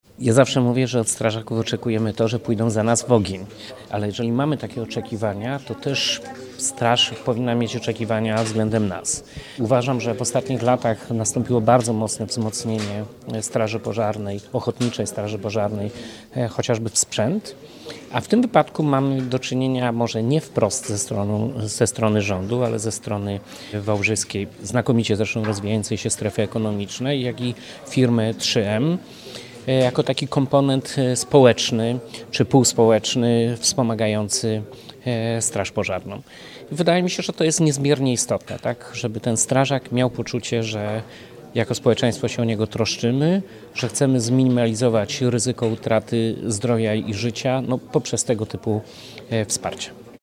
– Strażak powinien mieć poczucie, że jako społeczeństwo się o niego troszczymy – mówi Jarosław Obremski, Wojewoda Dolnośląski.